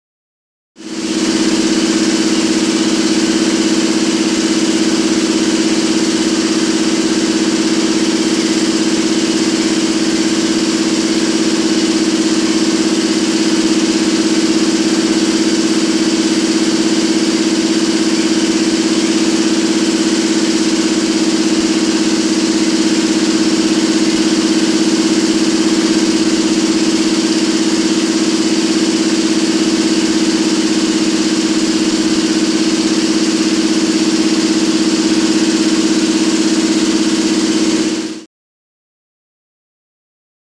Generator; Exterior Constant Generator.